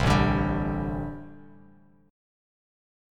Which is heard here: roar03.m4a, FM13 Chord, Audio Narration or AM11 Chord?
AM11 Chord